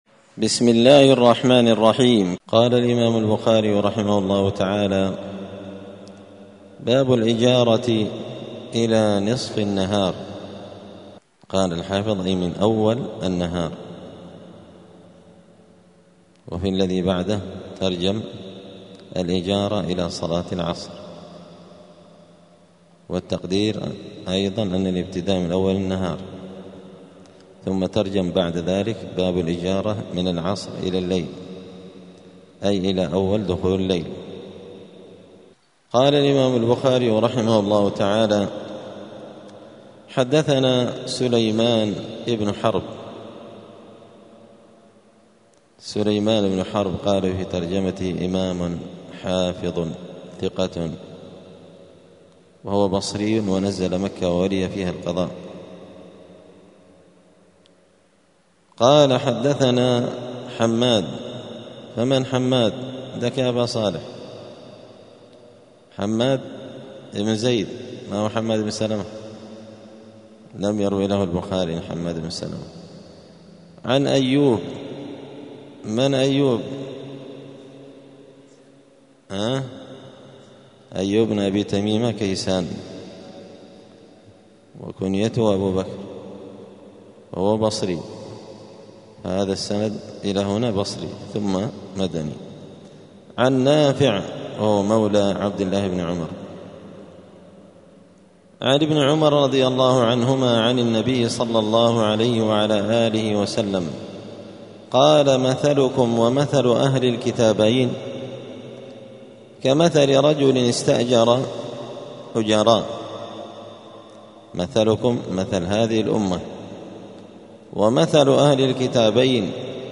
دار الحديث السلفية بمسجد الفرقان قشن المهرة اليمن
الأربعاء 7 محرم 1447 هــــ | الدروس، دروس الحديث وعلومه، شرح صحيح البخاري، كتاب الإجارة من صحيح البخاري | شارك بتعليقك | 5 المشاهدات